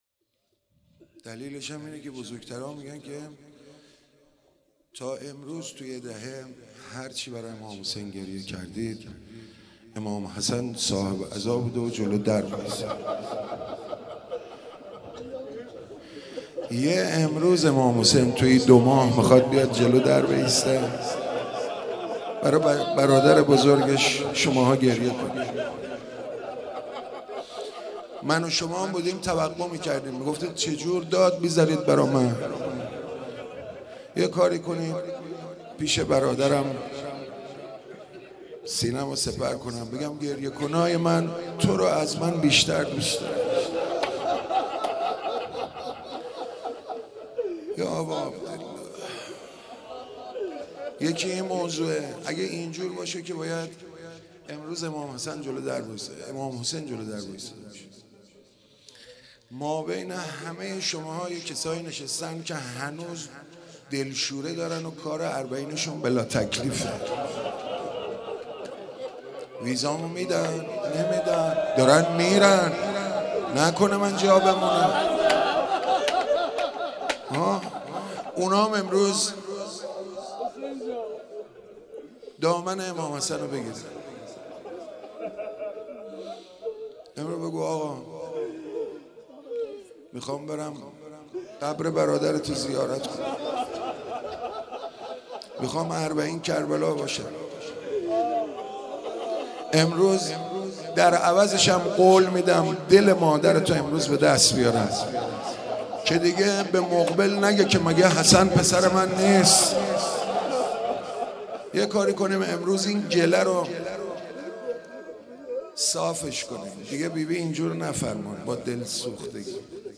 روضه امام حسن